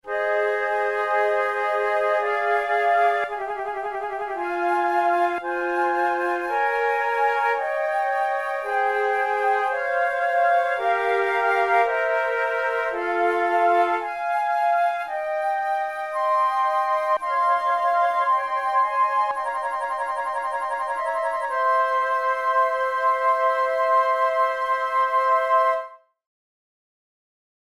InstrumentationFlute trio
KeyF minor
Tempo56 BPM
Baroque, Sonatas, Written for Flute